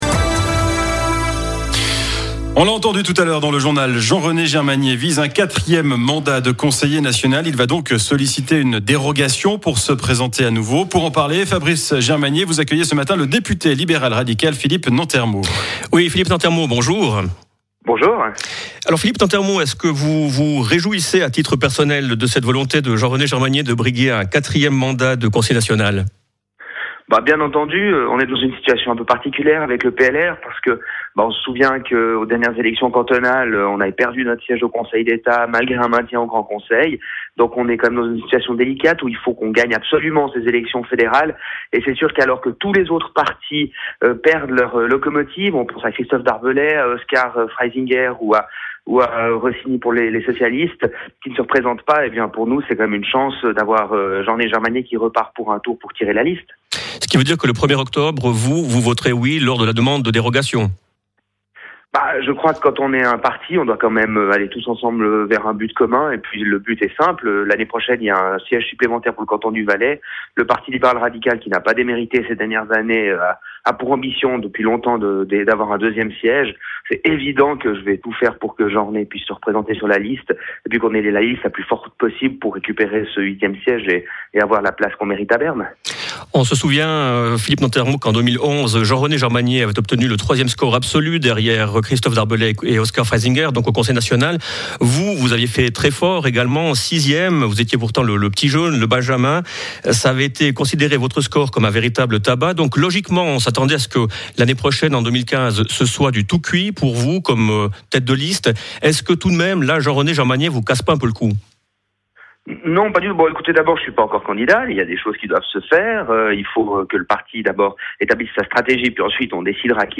Mon intervention sur Rhône FM à ce sujet.